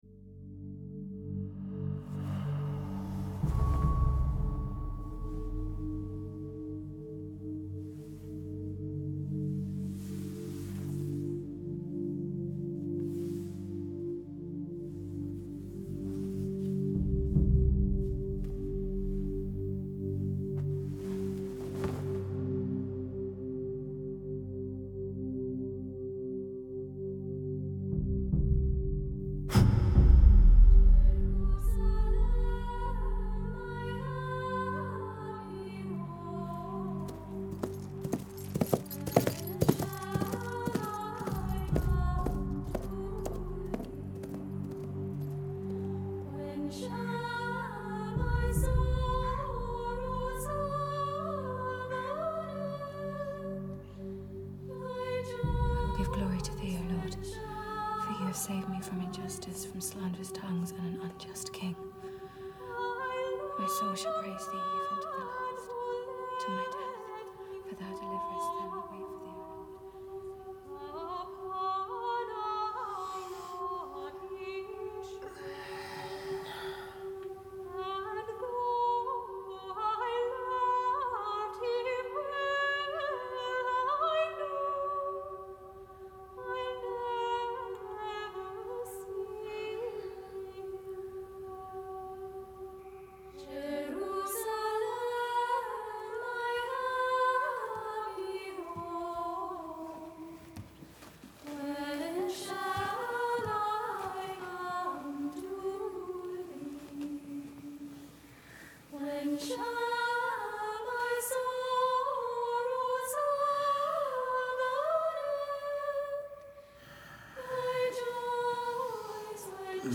The music is a hymn that’s heavy on the lamenting.